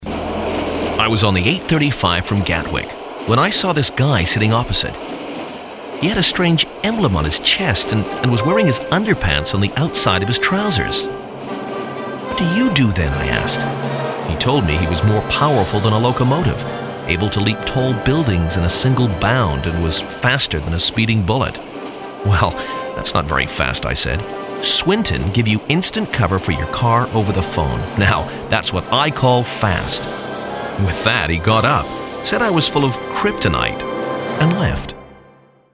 Male, 40s, 50s, 60s, American, DJ, The Kid, commercial, advert, voiceover, voice over, DGV, Damn Good Voices, damngoodvoices, Crying Out Loud, cryingoutloud,